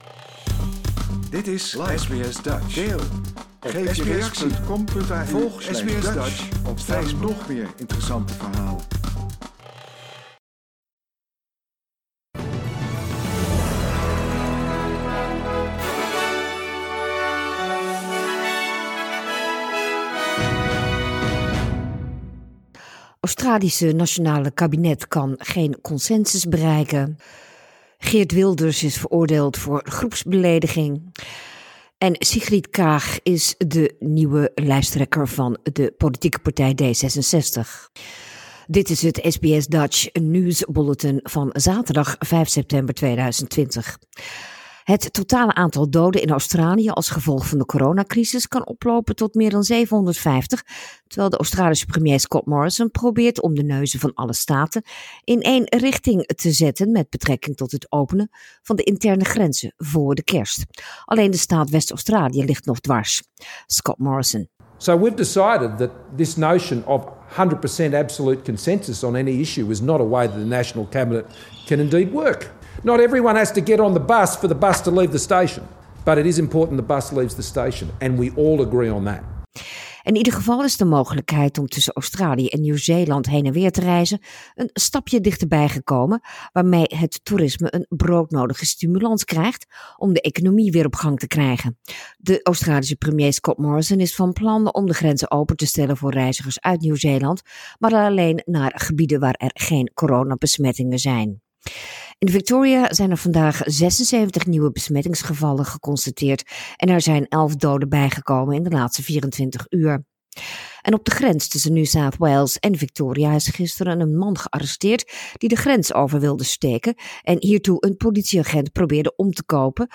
Nederlands/Australisch SBS Dutch nieuwsbulletin zaterdag 5 september 2020
dutch_0509_news_0.mp3